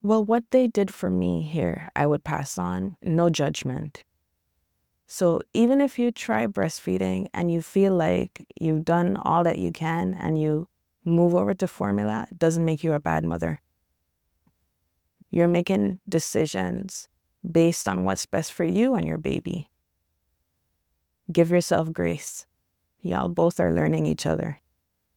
Testimonial 1 - WIC participant